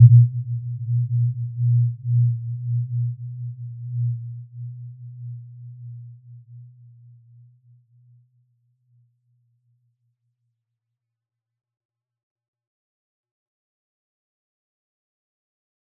Warm-Bounce-B2-f.wav